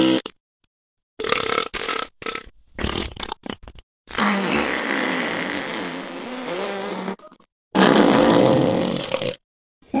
Знатные звуки пердежа
toilet1.wav